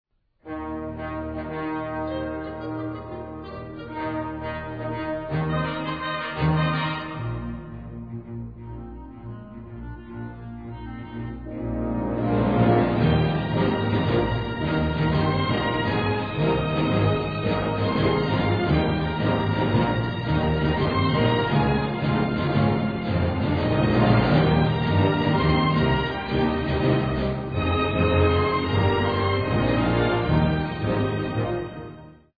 Allegro Vigoroso